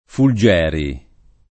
[ ful J$ ri ]